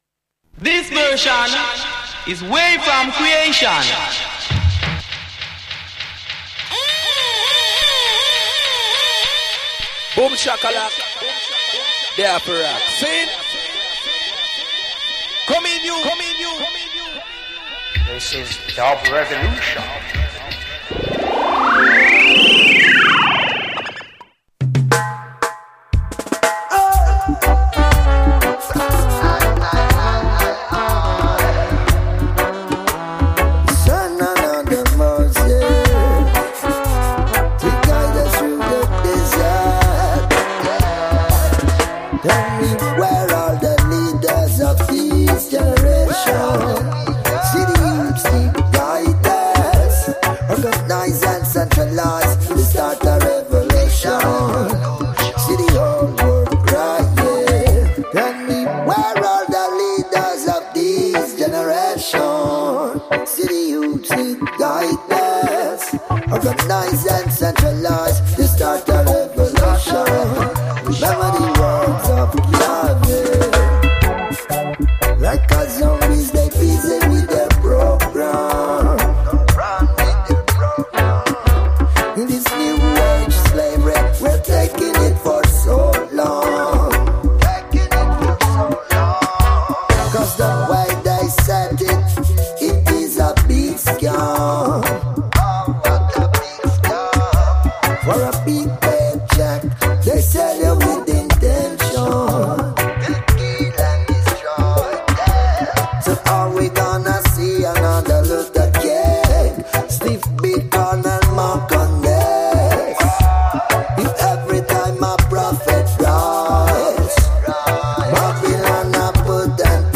Le dub est un style musical issu du reggae jamaïcain, qui met en avant le couple rythmique basse et batterie et des effets de son avec un remixage réalisé en temps réel !